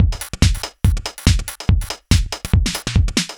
Index of /musicradar/uk-garage-samples/142bpm Lines n Loops/Beats
GA_BeatA142-03.wav